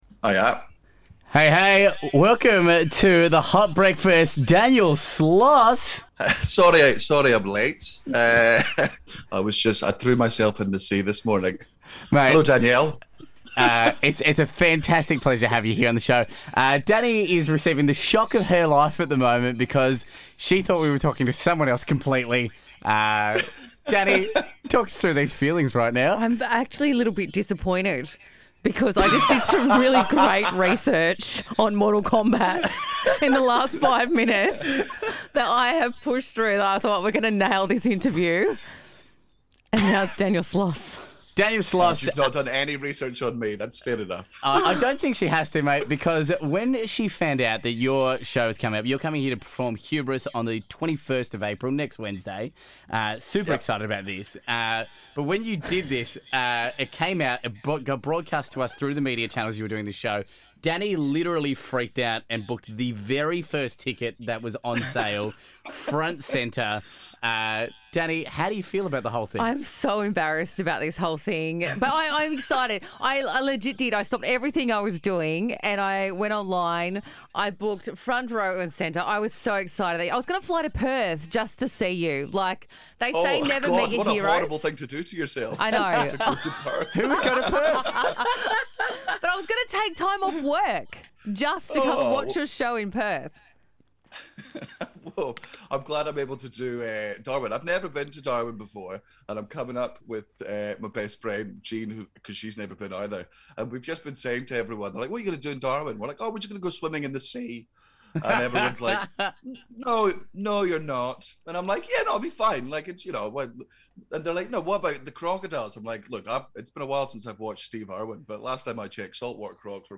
shock interview